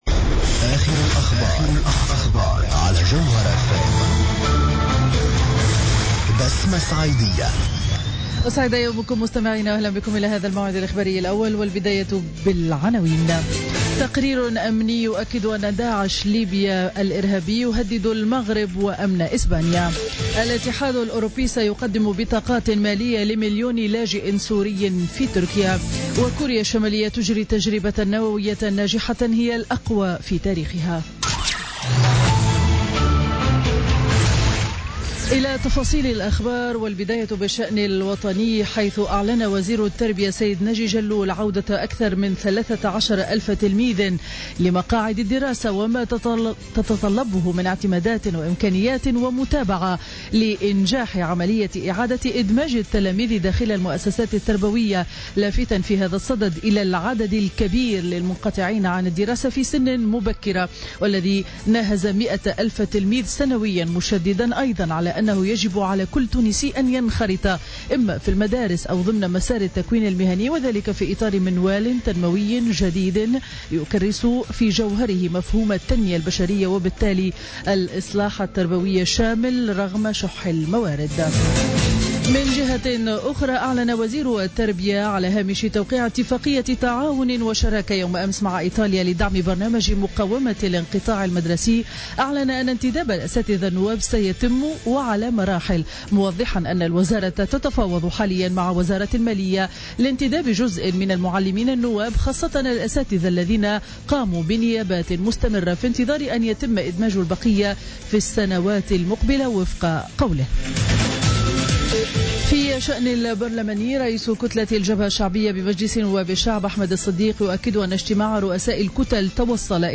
نشرة أخبار السابعة صباحا ليوم الجمعة 9 سبتمبر 2016